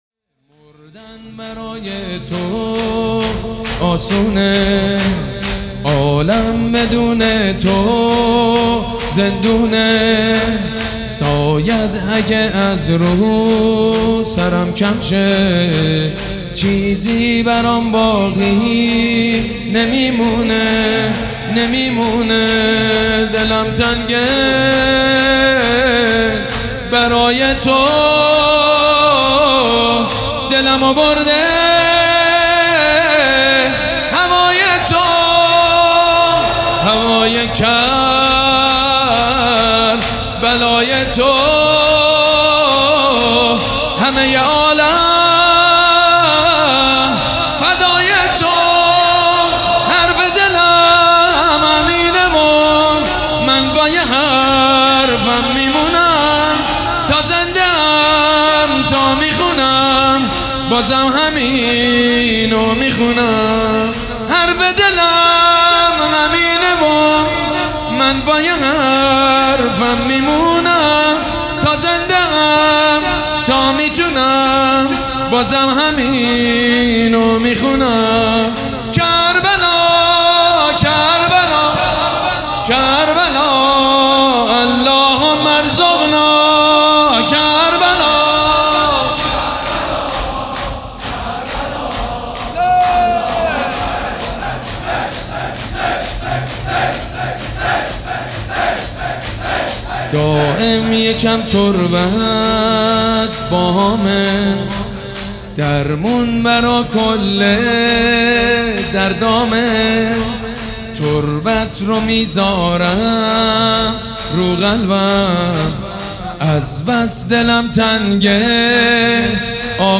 شور
مداح
حاج سید مجید بنی فاطمه
مراسم عزاداری شب اول